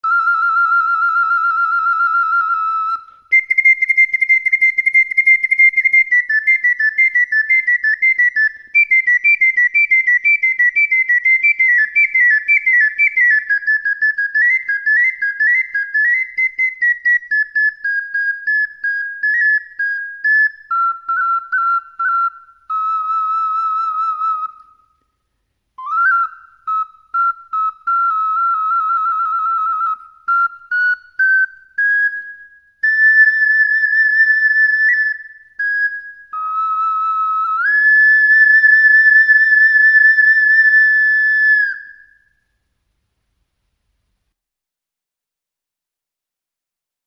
オカリナ
音符をクリックすると管理人のデモ演奏が聴けます。
だいぶリバーブに助けられちゃってます。
ピッコロＣ管。とても甲高い音。
更に枯れたキツイ音がします。
soprano-c-oca-samplemusic.mp3